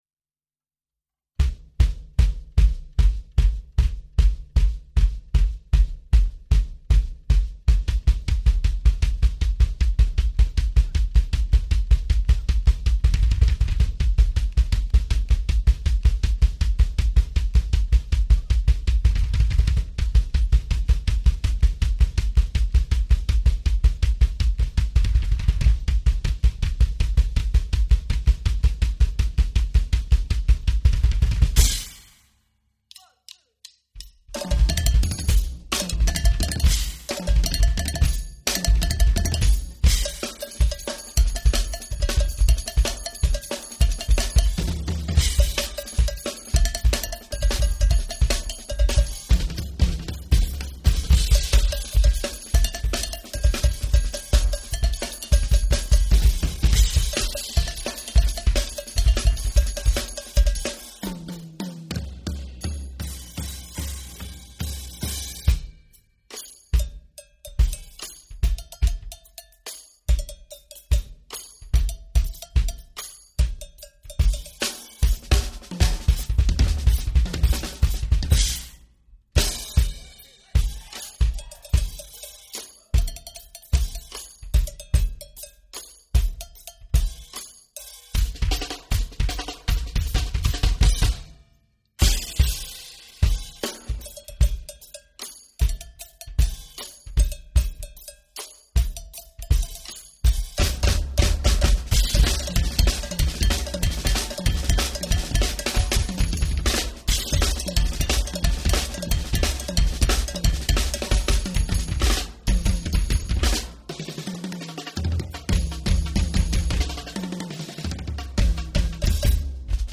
DAY 6, freedomsjazz festival 2016
two young drummers